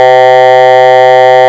c-chord.wav